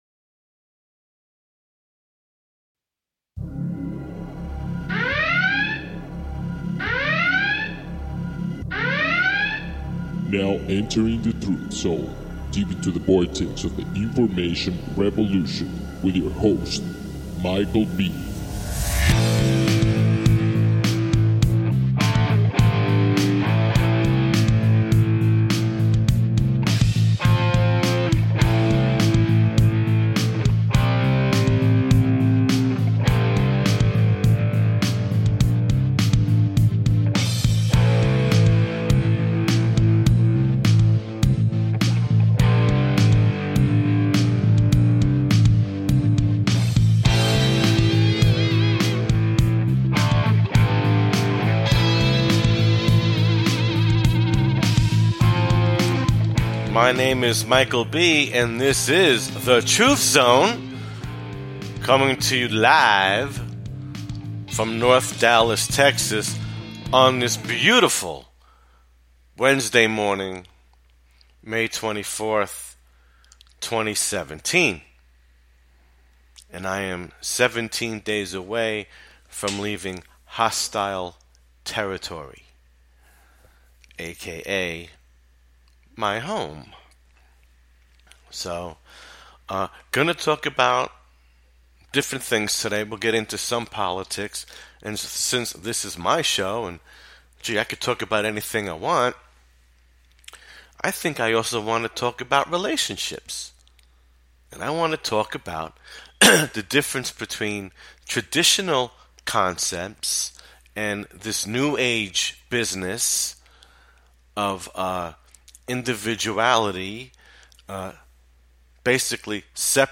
The Truth Zone is in your face radio and not for the weak of heart.